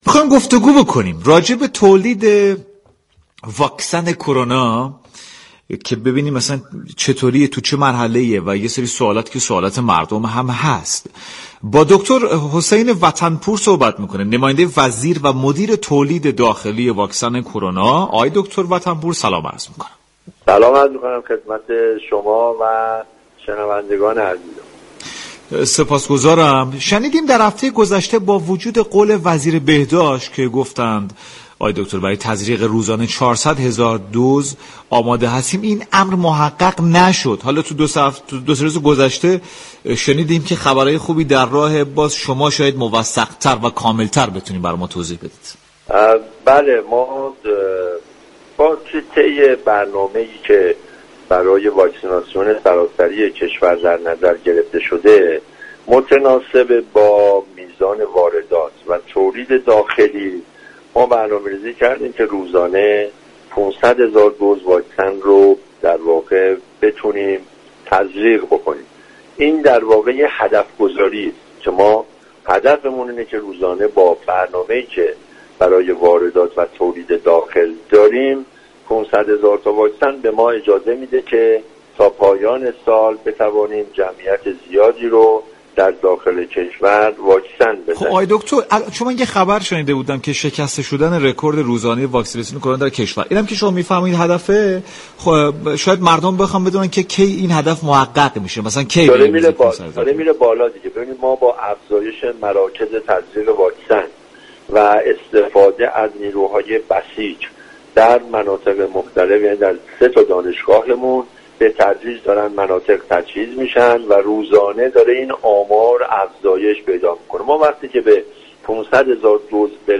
به گزارش پایگاه اطلاع رسانی رادیو تهران، حسین وطن پور نماینده وزیر و مدیر اجرایی تولید واكسن داخلی كرونا در گفتگو با برنامه سعادت آباد رادیو تهران با اشاره به عدم تحقق واكسیناسیون روزانه 400 هزار دُز واكسن گفت: هدف برنامه ریزی ما متناسب با واردات و تولید داخلی با ظرفیت واكسیناسیون روزانه 500 هزار دُز صورت گرفت.